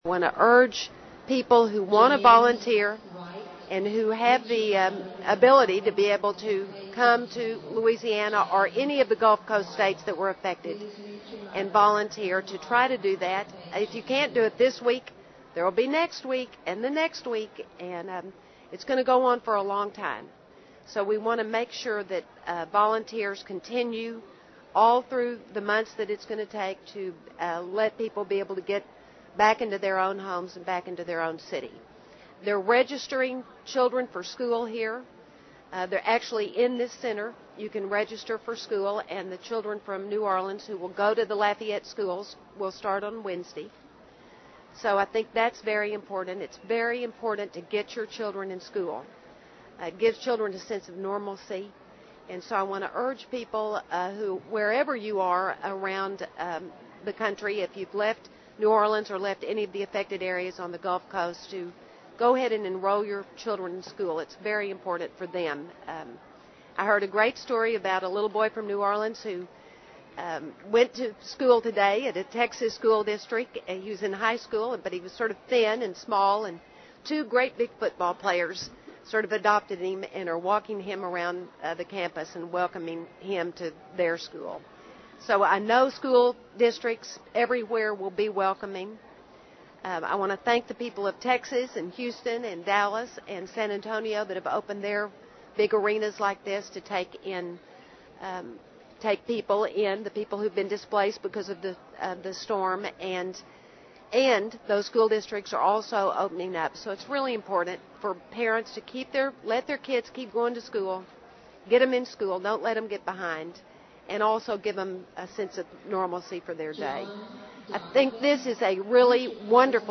美国第一夫人就水灾情况答记者问 听力文件下载—在线英语听力室